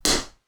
slap2.wav